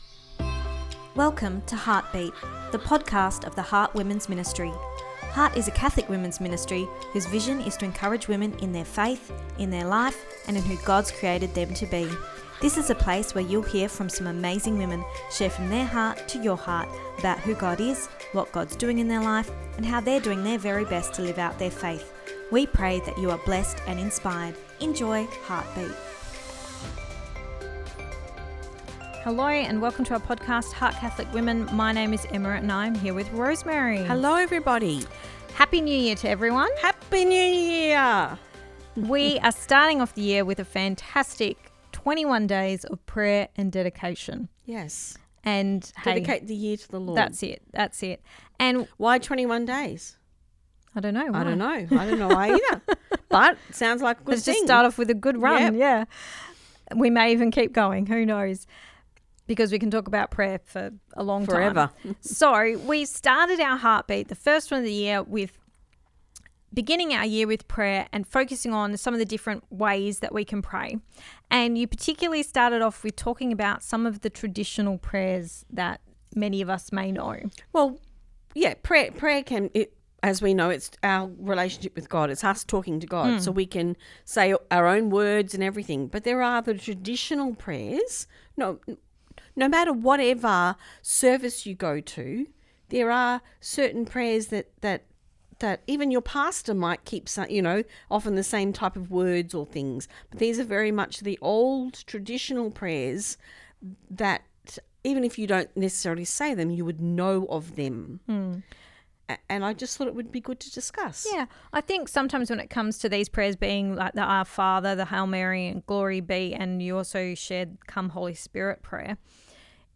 Ep279 Pt2 (Our Chat) – Begin with Prayer